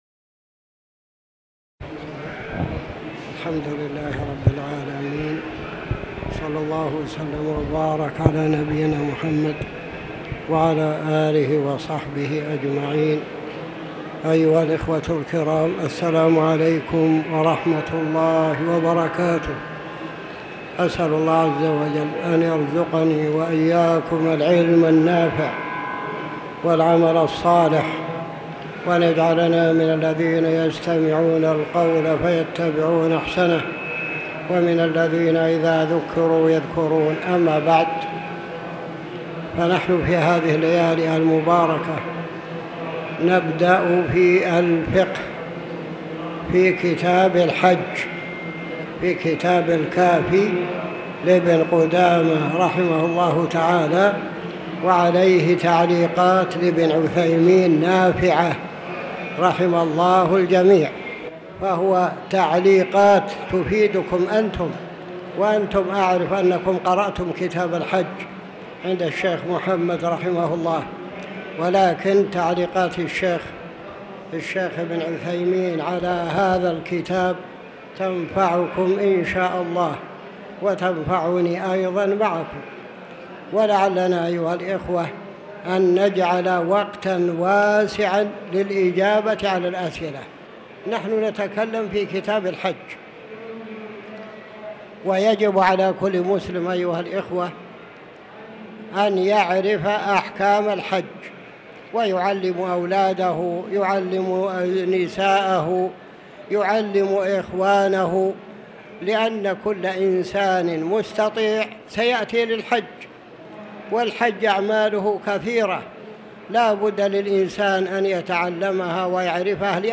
تاريخ النشر ١٩ ذو القعدة ١٤٤٠ هـ المكان: المسجد الحرام الشيخ